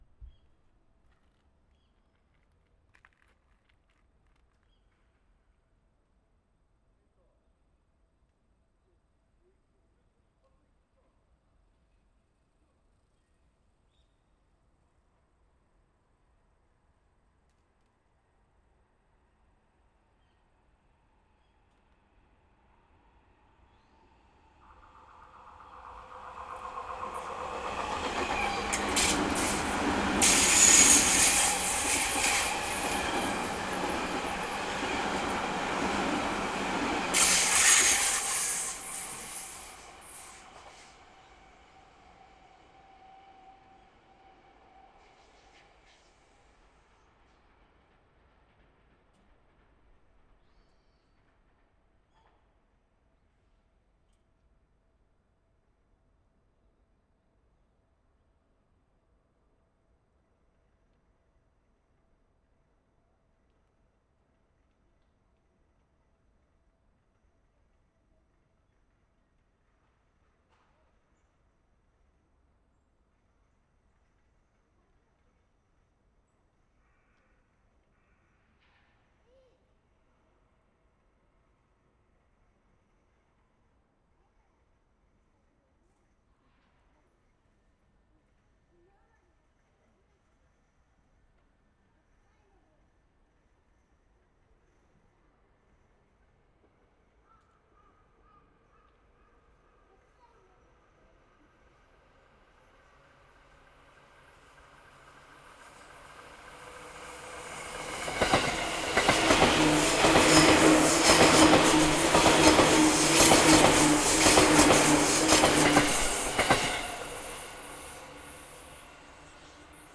西武池袋線「清瀬カーブ」でテスト録音
下り電車通過。
しばらくして、上り電車通過。
TR1とTR2の録音したファイルを、サウンドフォージでステレオにしてから、
－3dBをピークにノーマライズしました。
オーディオテクニカ AT9943＋Rycote BBG Windjammer
サンプリングレート/48kHz、LO CUT/OFF